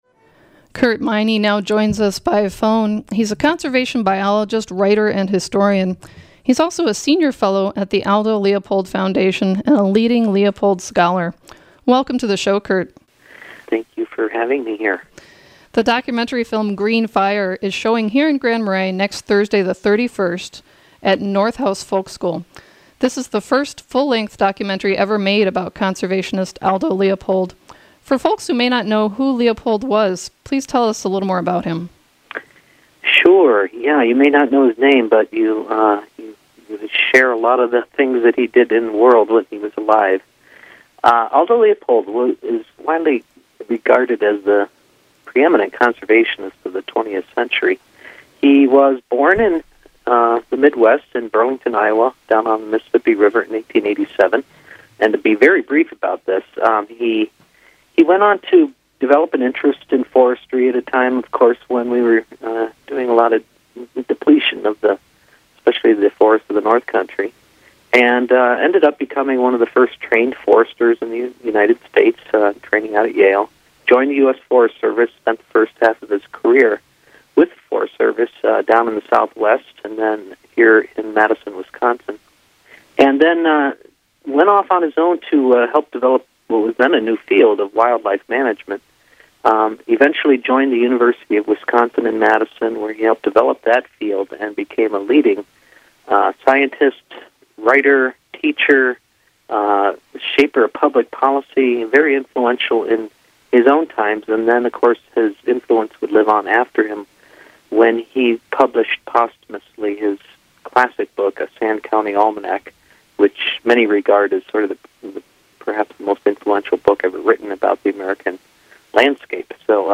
The movie "Green Fire: Aldo Leopold and a Land Ethic For Our Time" is set to show at 7 p.m.  Thursday, May 31st, at North House Folk School in Grand Marais - part of the Boreal Birding and Northern Landscapes Festival.   In this interview